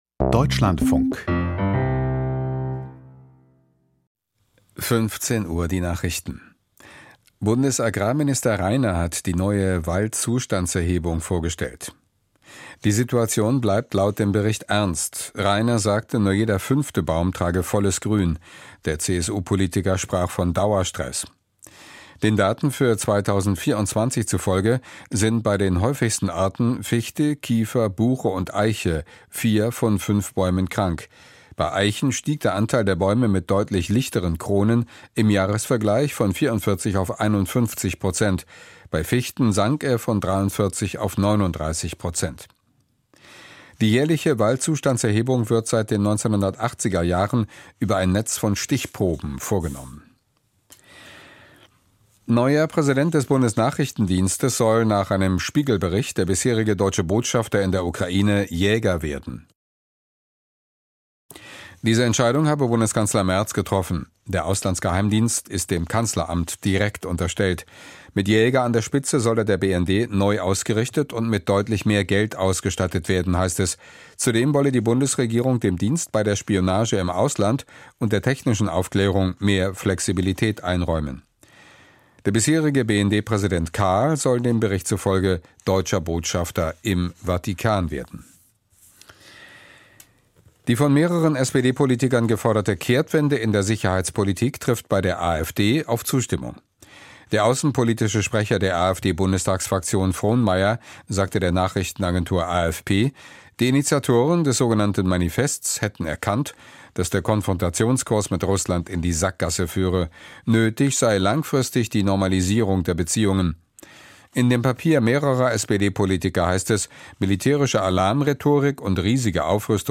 Steinmeier bei Netanjahu: Interview